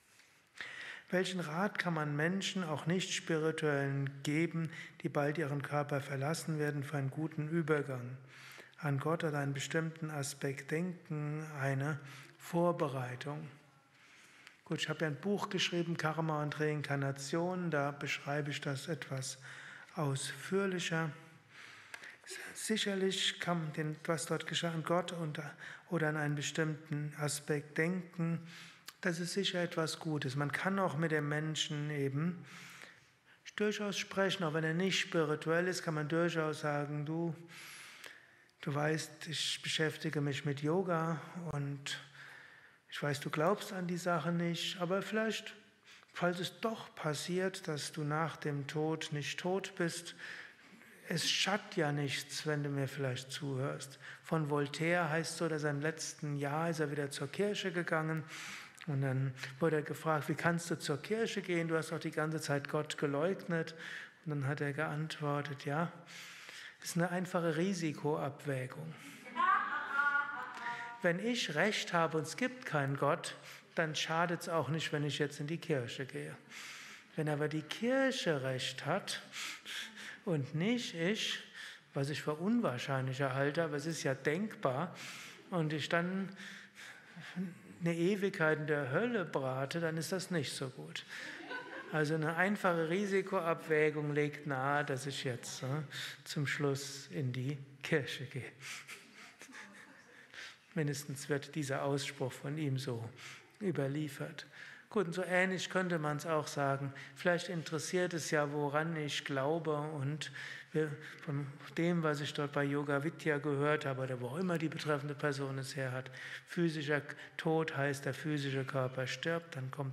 Kurzvorträge
Vidya, eine Aufnahme während eines Satsangs gehalten nach einer
Meditation im Yoga Vidya Ashram Bad Meinberg.